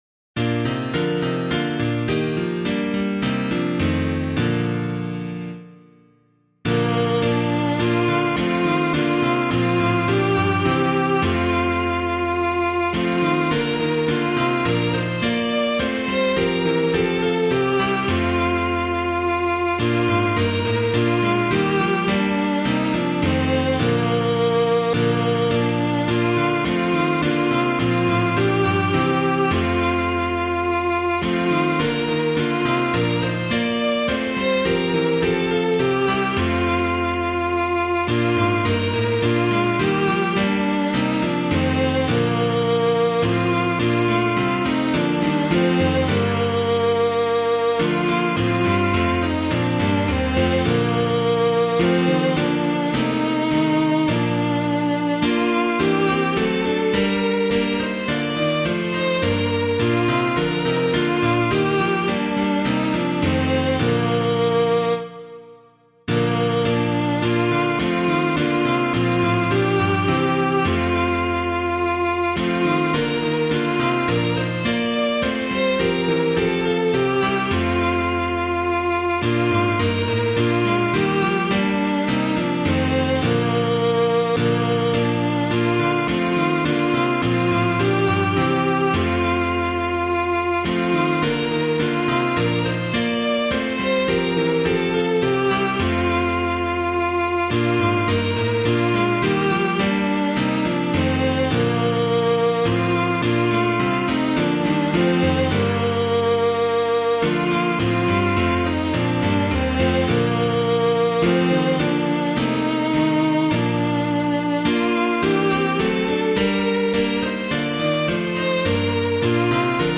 Public domain hymn suitable for Catholic liturgy.
Wake Awake for Night Is Flying [Winkworth - WACHET AUF] - piano.mp3